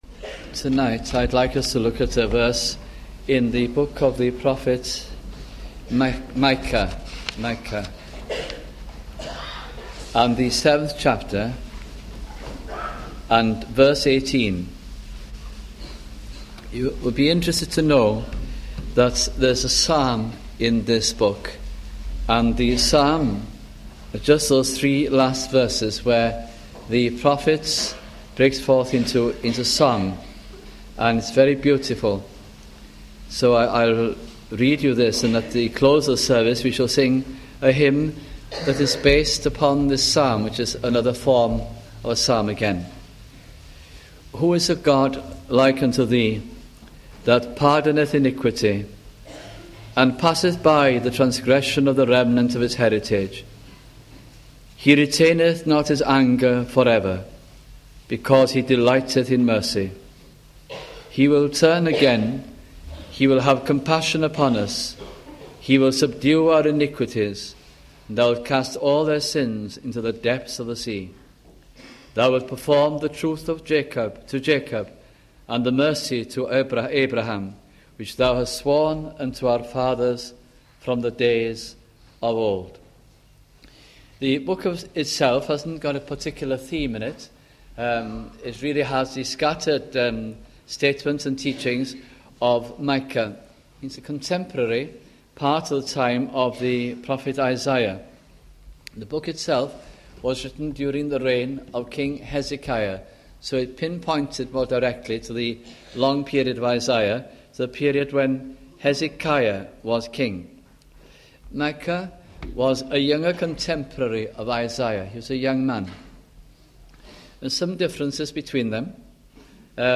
» Micah Gospel Sermons